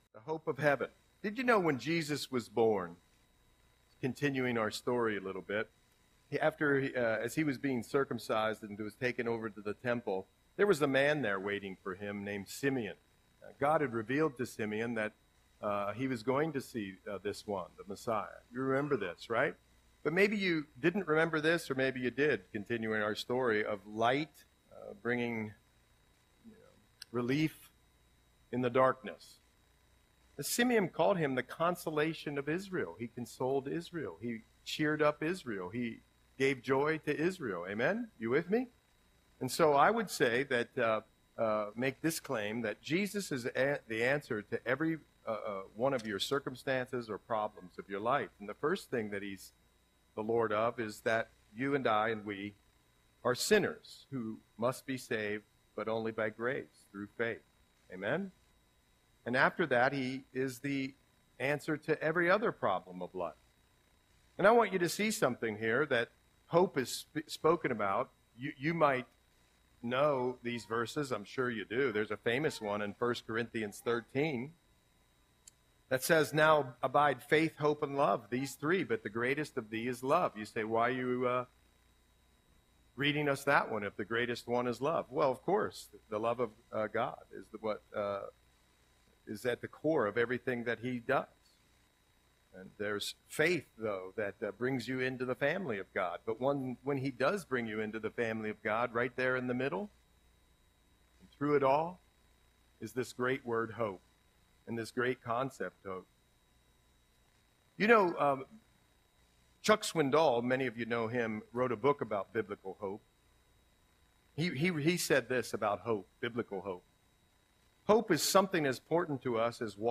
Audio Sermon - December 25, 2024